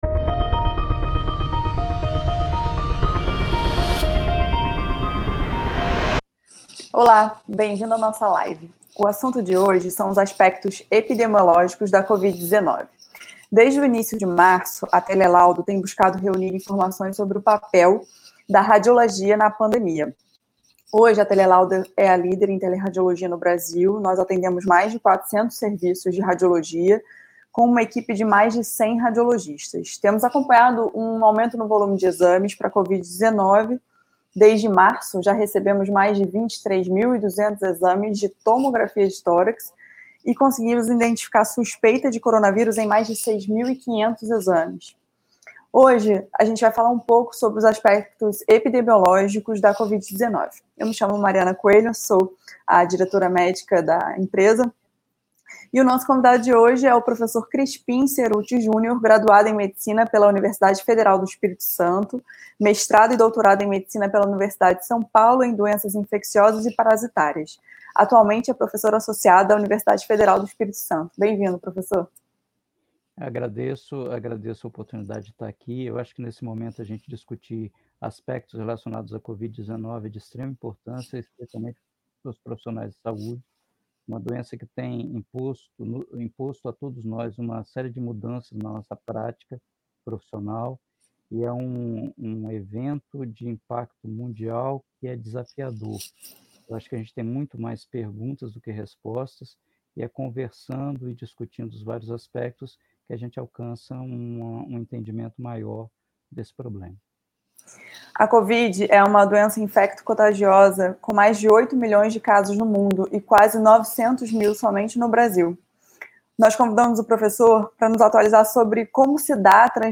Neste post, você encontra somente o áudio da live.